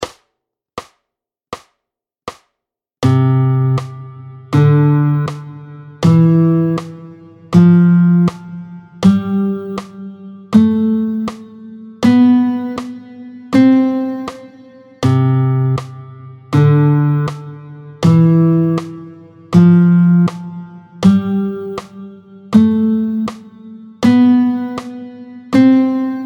Luth
09-02 La gamme diatonique de Do majeur, tempo 80